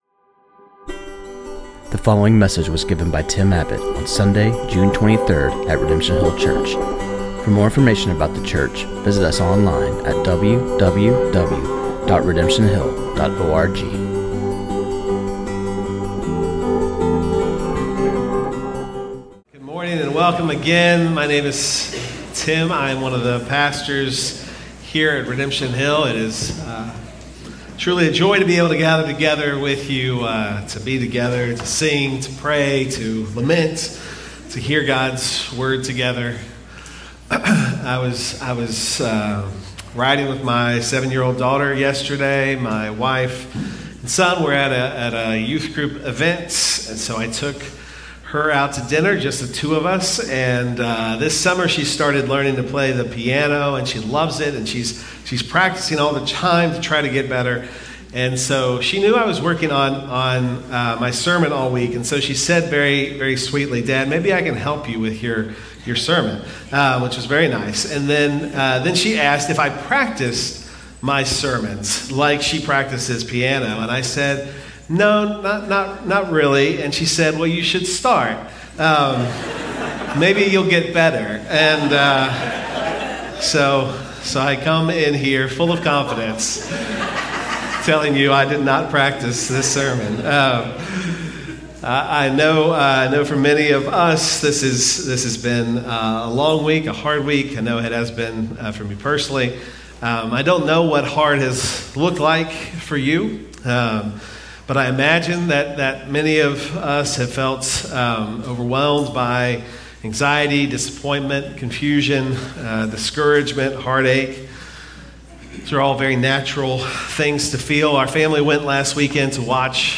This sermon on Psalm 34:1-22